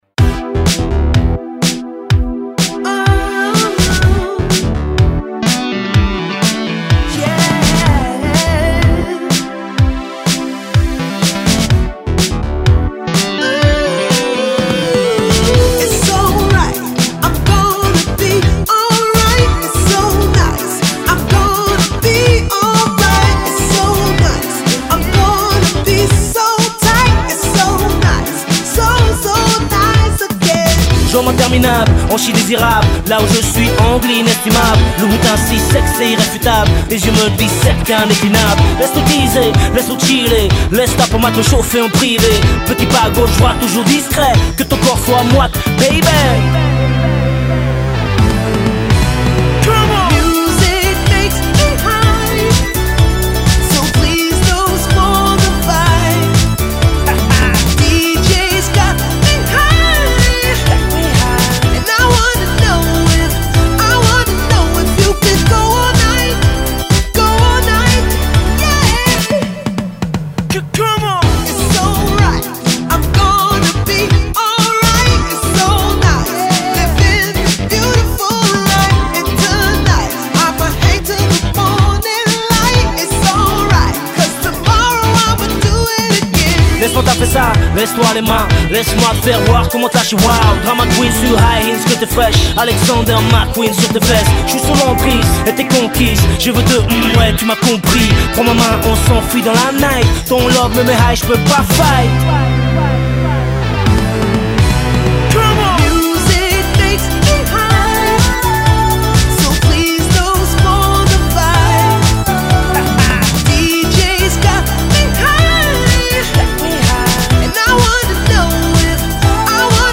Pop / Hip-hop.